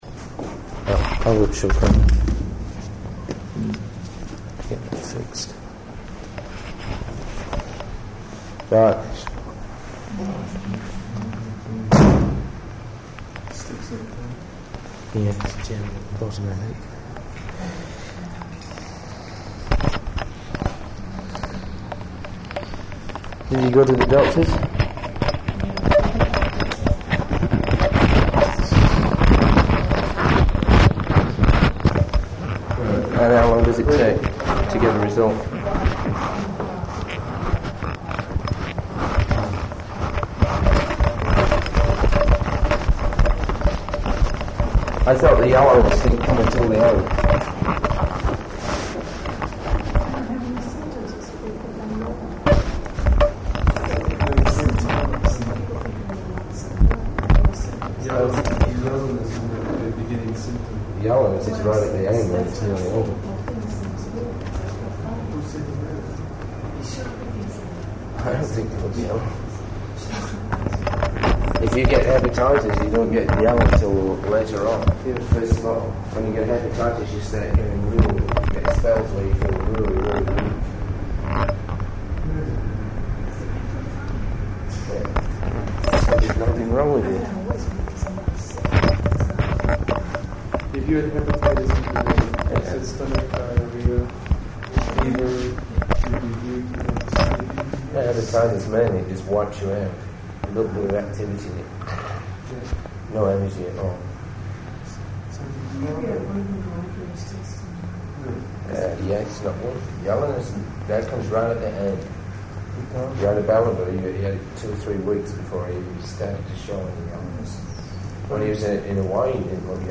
Room Conversation
Type: Conversation
Location: Detroit